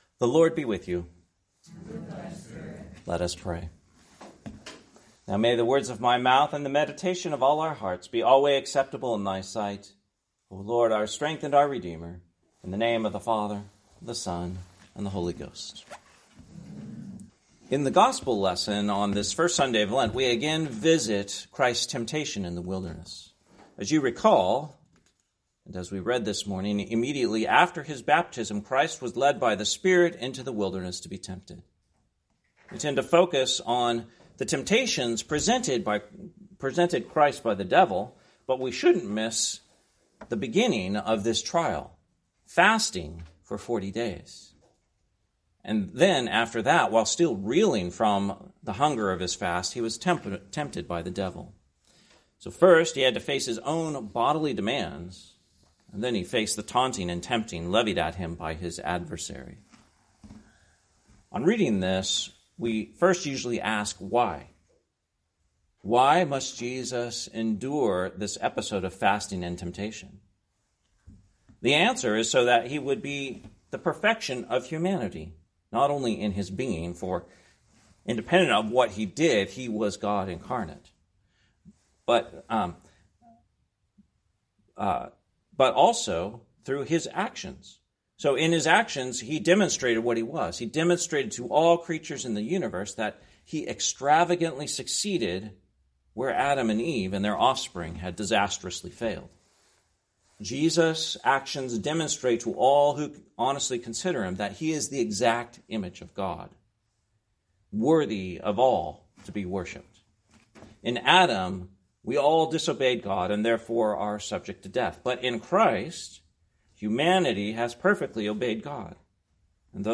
Sermon, First Sunday in Lent, 2024